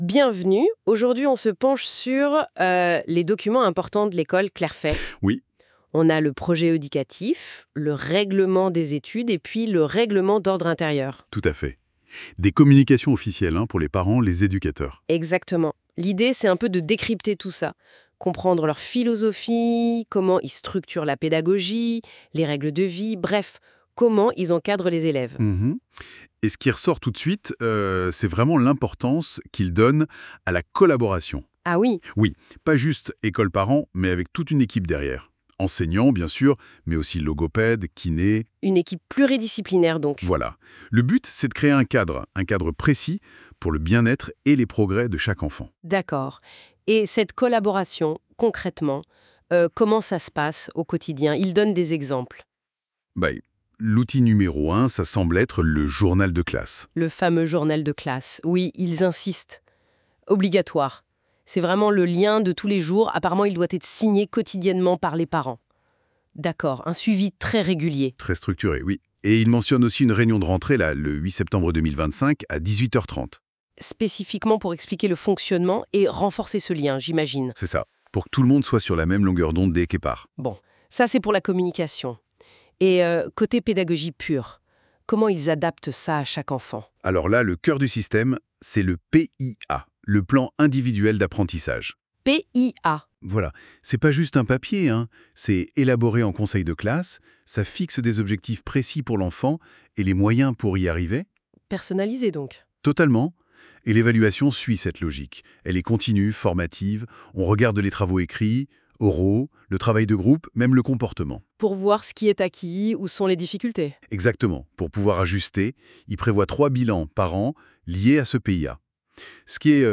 Informations aux parents en mode interview - version audio
En cliquant sur le fichier ci-dessus, vous découvrirez une interview des informations à destination des parents